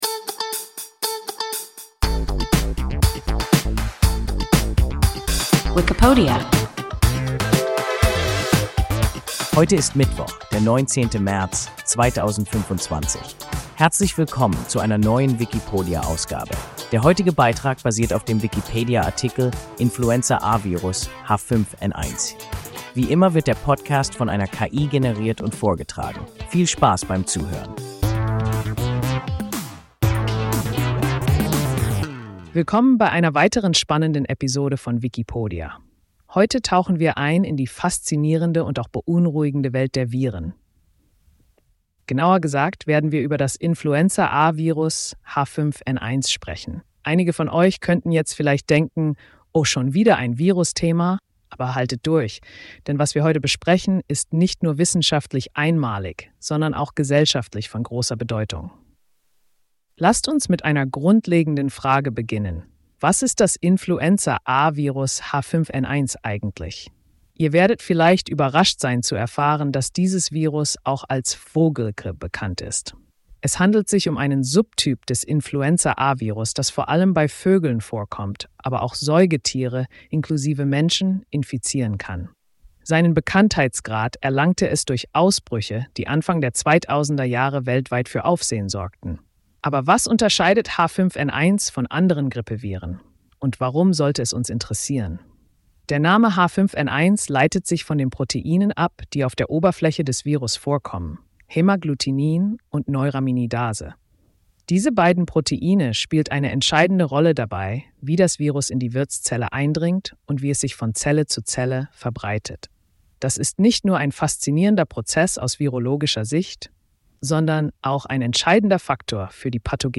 Influenza-A-Virus H5N1 – WIKIPODIA – ein KI Podcast